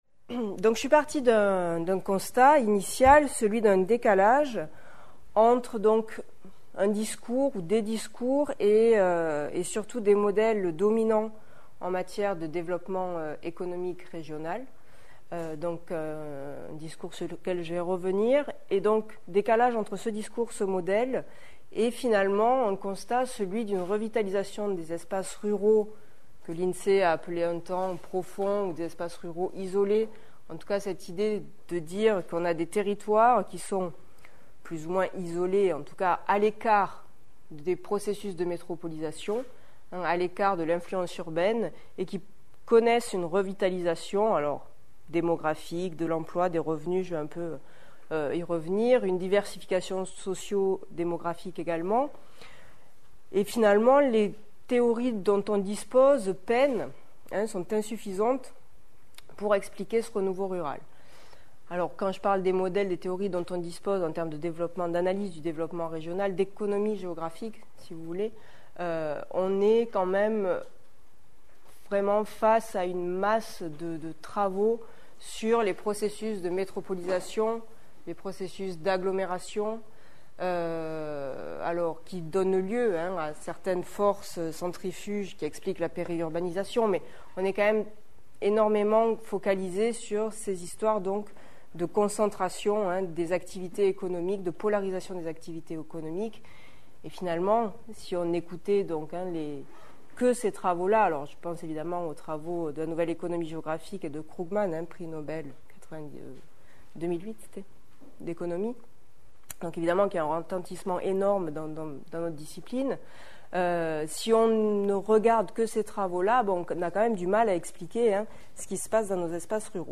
Cours/Séminaire
Lieu de réalisation MRSH Caen